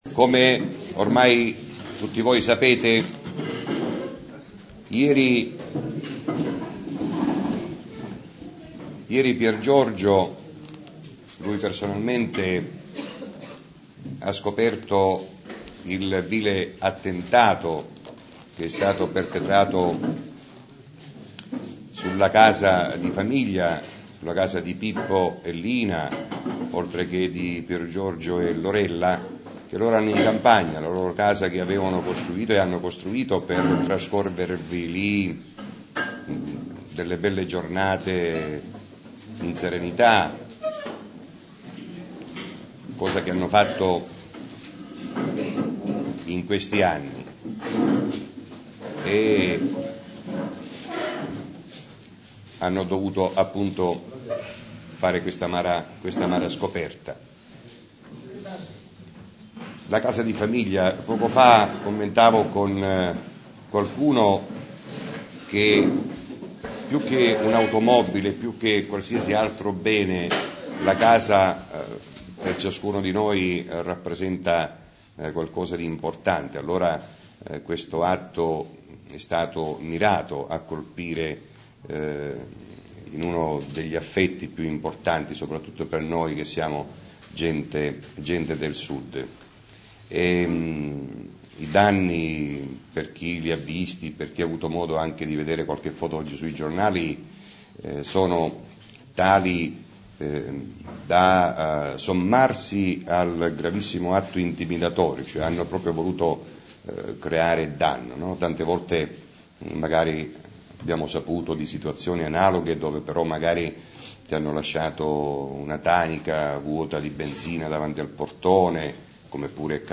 conferenza stampa PD